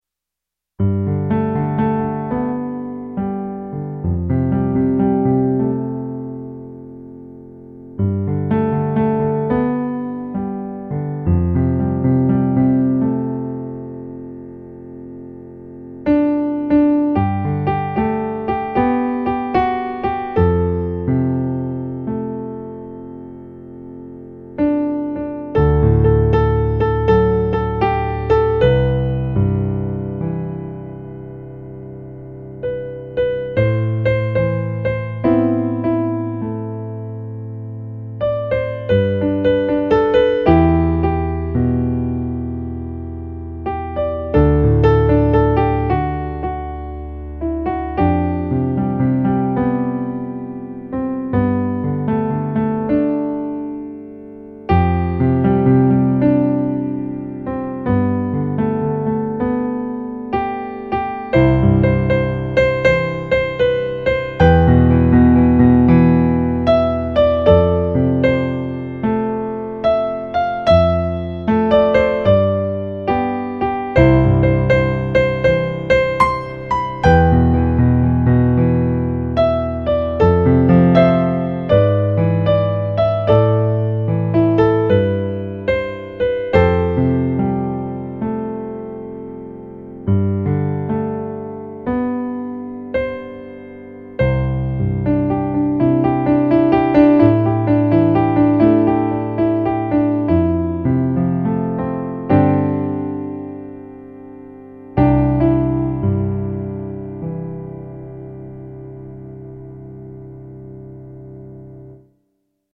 contains eight piano solo arrangements.
New Age remix